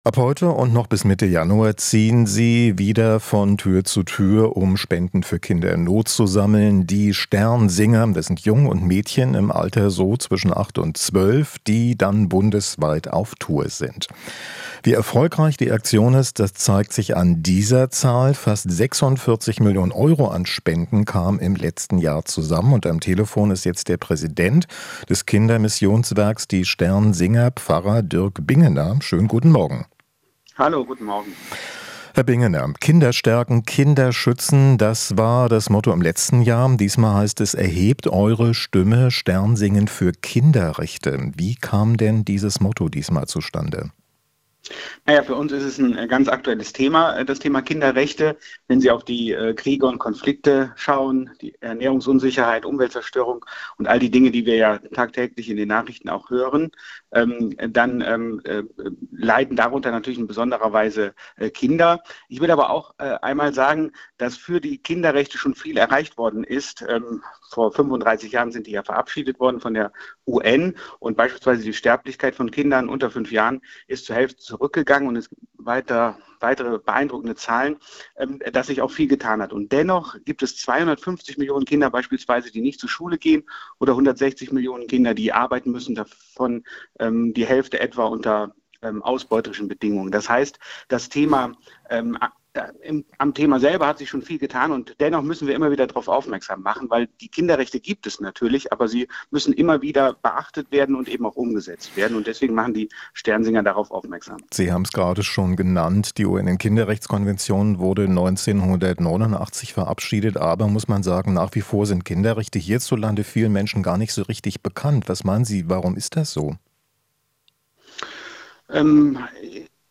Interview - Sternsinger sammeln Geld für Kinderprojekte